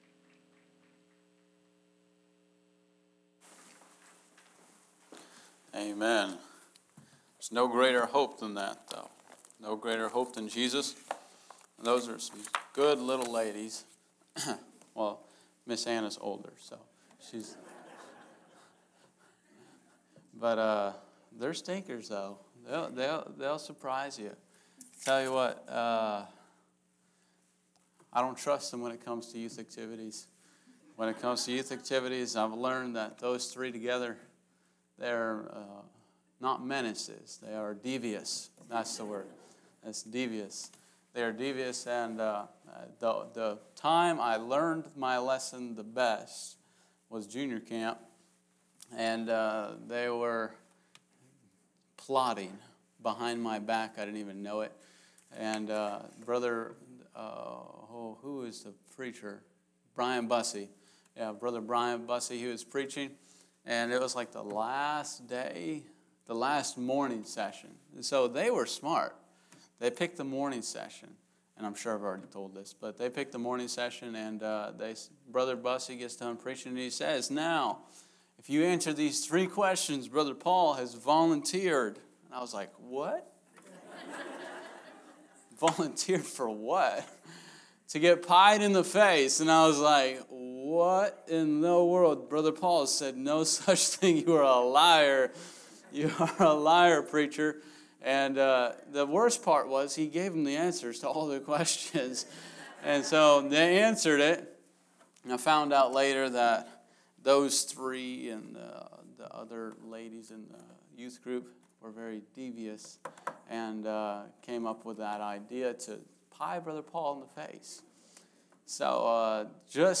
From the pulpit of Bethel Baptist Church